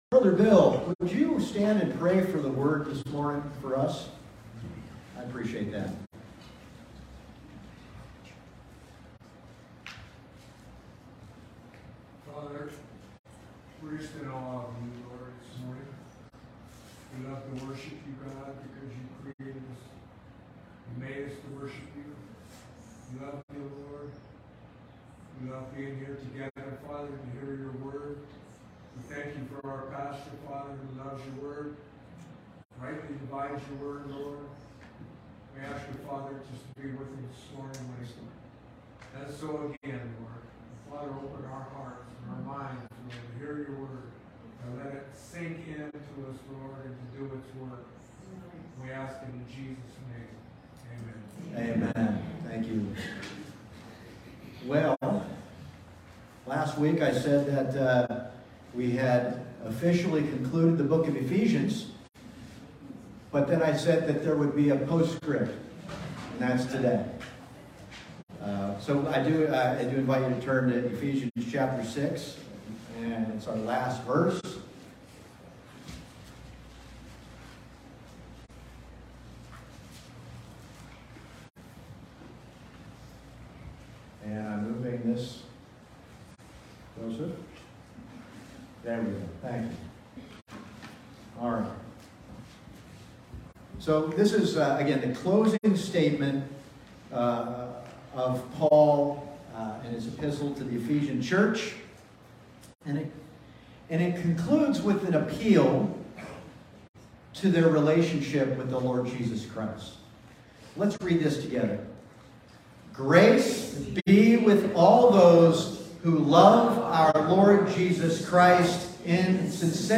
Passage: Ephesians 6:24, Revelation 2:1-7 Service Type: Sunday Morning